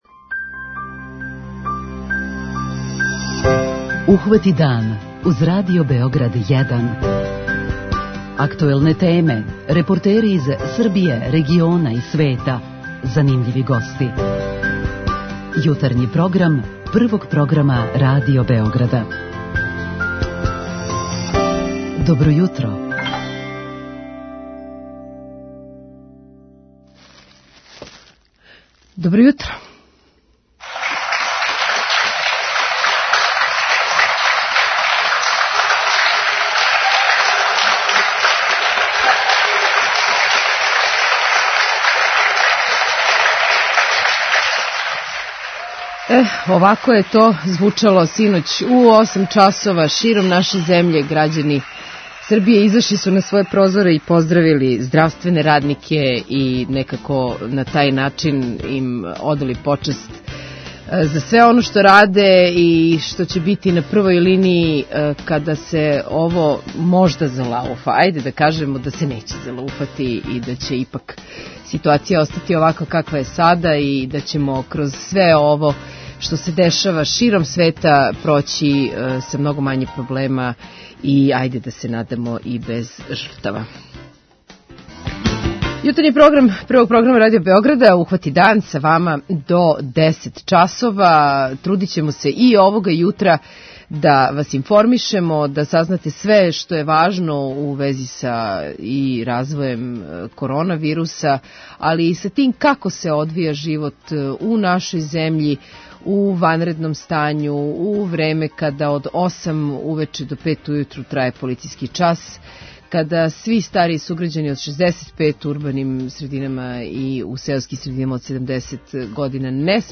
Почетак данашњег Јутарњег програма обележиће вести, које су - и у нашој земљи, и у свету - обојене све снажнијим присуством и распростирањем вируса корона, као и утицајем инфекције на, практично, све сфере јавног и приватног живота.